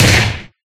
Blow6.ogg